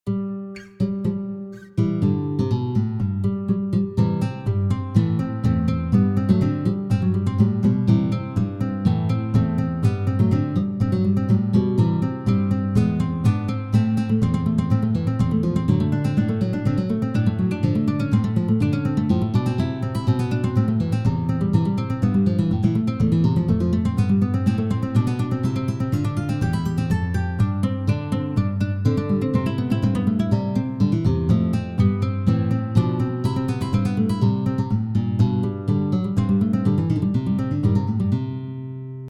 Arrangements for solo guitar of the